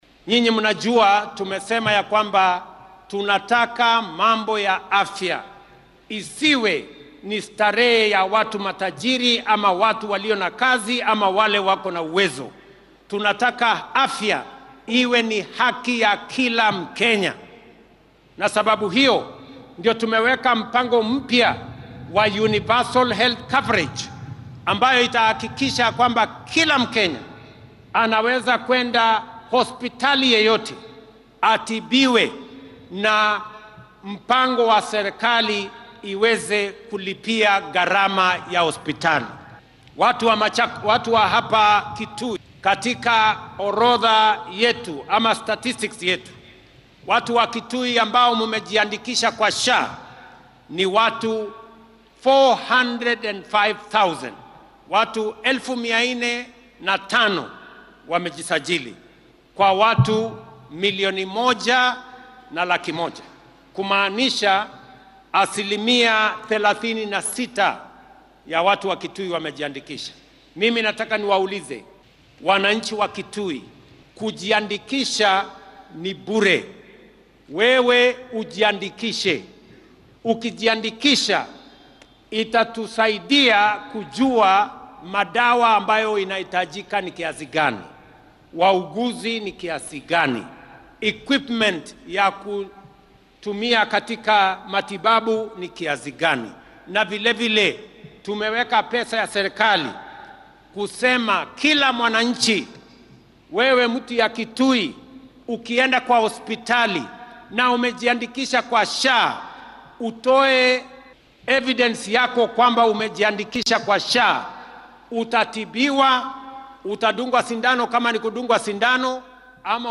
Xuska Mashuja Day ayaa maanta lagu qabtay garoonka Ithookwe ee ismaamulka Kitui.
Madaxweyne Ruto oo ka hadlay arrimaha caafimaadka ayaa sheegay in qof walba oo Kenyaan ah uu xaq u leeyahay helidda daryeel caafimaad oo tayaysan.